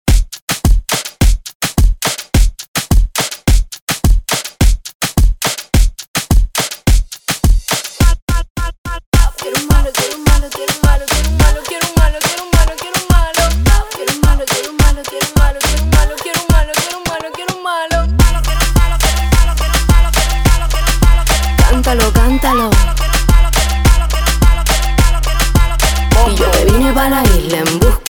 His style is unique and electrifying.
DJ